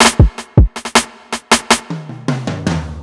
smooth ride drumfill.wav